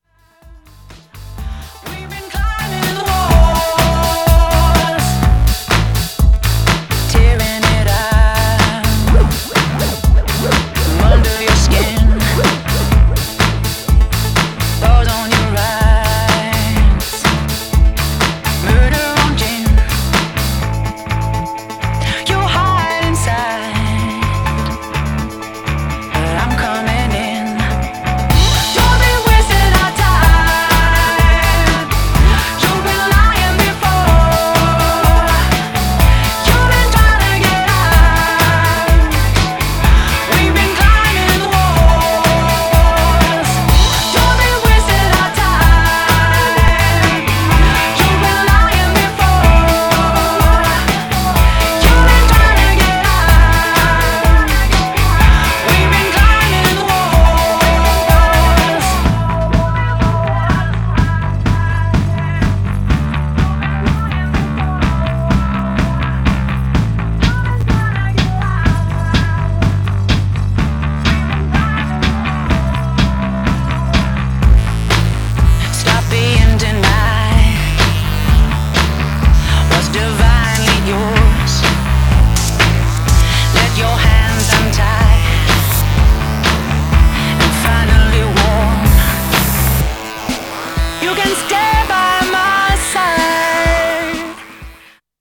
Styl: Progressive, House, Breaks/Breakbeat